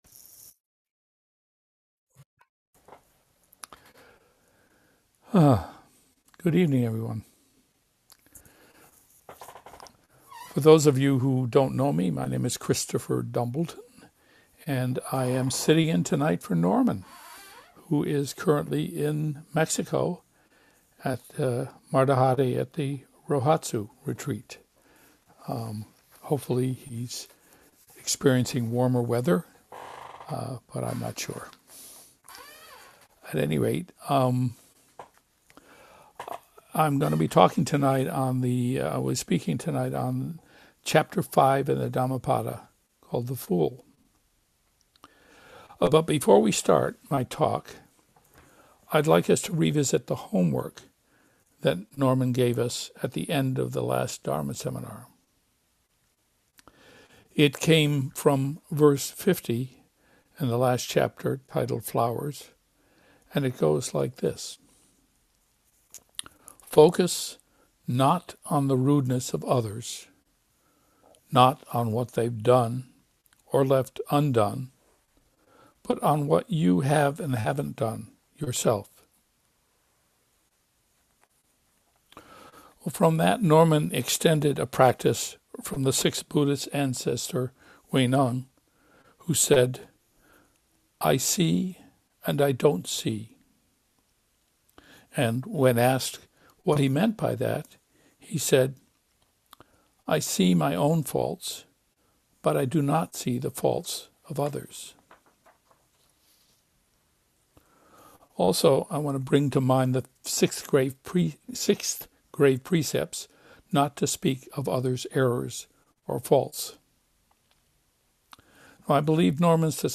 gives the fourth talk of the Dhammapada series to the Everyday Zen dharma seminar. The Dhammapada or “Path of Dharma” is a collection of verses in the Pali Canon that encapsulates the Buddha’s teachings on ethics, meditation and wisdom and emphasizes practical guidance for living a virtuous life.